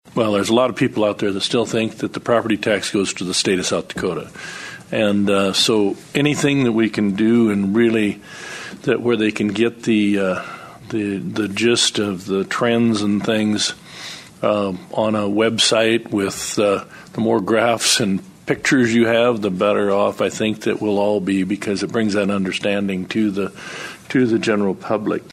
Senator Gary Cammack of Union Center says there is a lot of misunderstanding about ag land taxes.